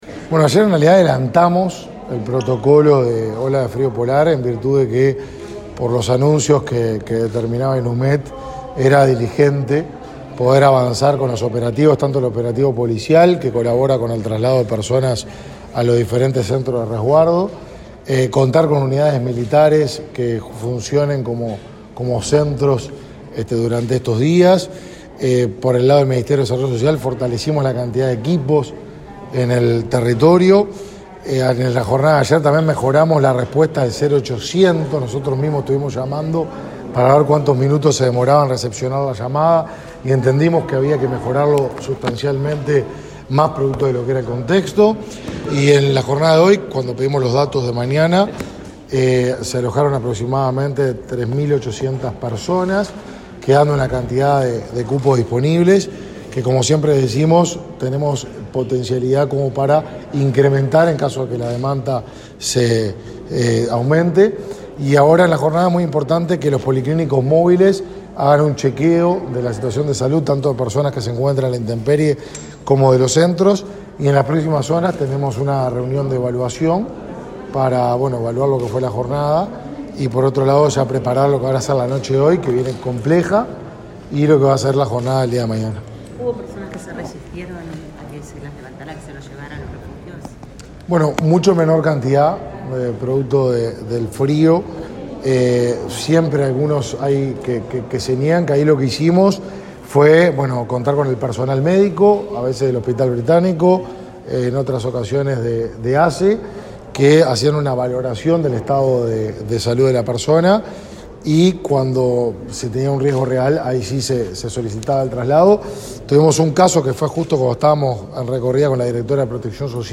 Declaraciones a la prensa del ministro de Desarrollo Social, Martín Lema
Luego, el ministro Lema dialogó con la prensa.